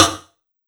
Snr Rok 01.wav